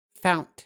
Ääntäminen
Southern England: IPA : /faʊnt/ GA: IPA : /faʊnt/ GA: IPA : /fɑnt/ Southern England: IPA : /fɒnt/ RP : IPA : /faʊnt/ IPA : /fɒnt/